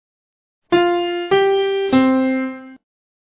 It is the full cadence, abbreviated as IV → V → I. Here is an example:
Full cadence